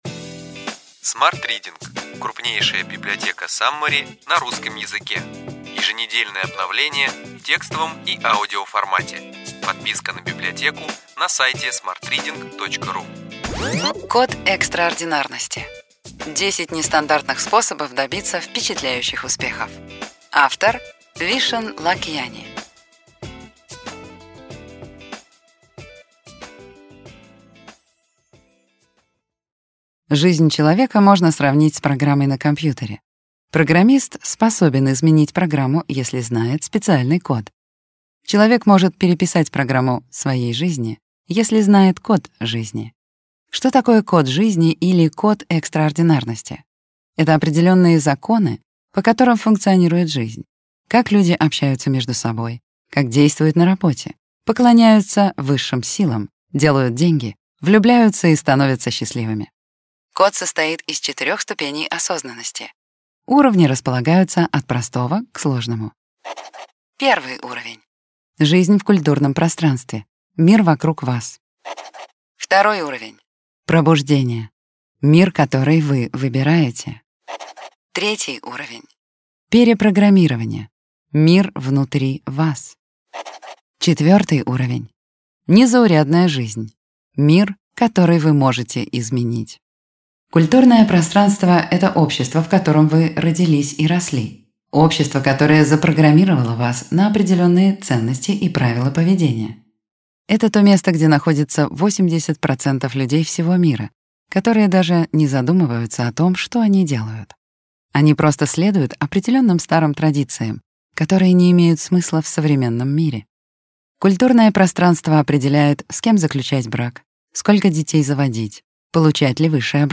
Аудиокнига Ключевые идеи книги: Код экстраординарности. 10 нестандартных способов добиться впечатляющих успехов.